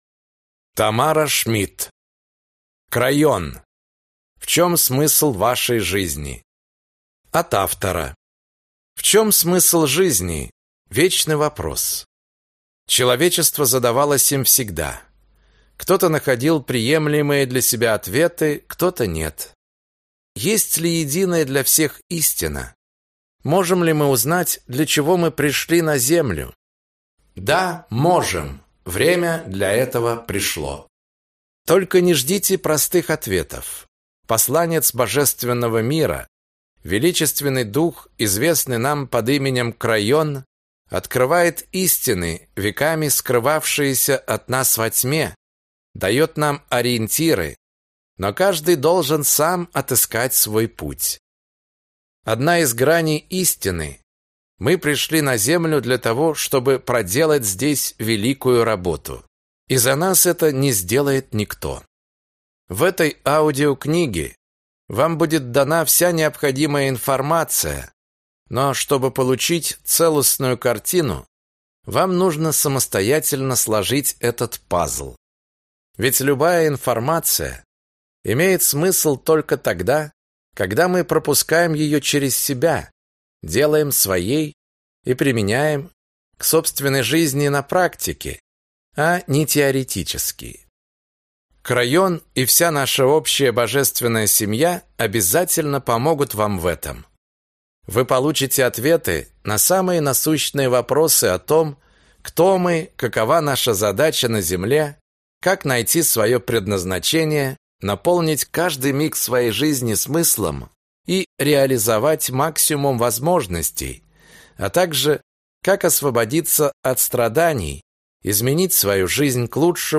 Аудиокнига Крайон. В чем смысл вашей жизни?